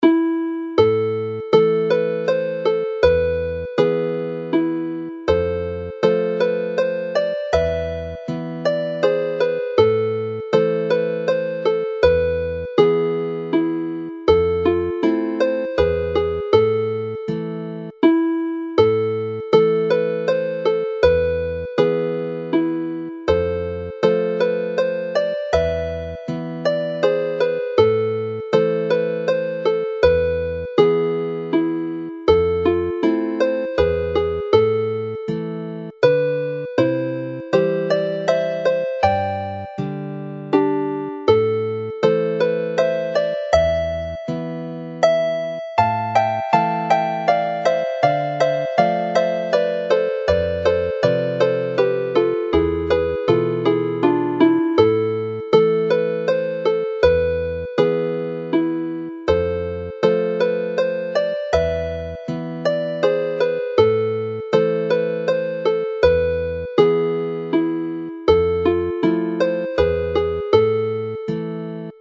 Glwysen uses only the F natural, giving the emotive tone-and-a-half gap between it and the G# in the last but one bar of section A. Robin Huw Bowen refers to this as 'the Welsh interval' and uses the piece to start the Clerorfa set, Y Cyfwng Cymreig based on that interval.
Melody and accompaniment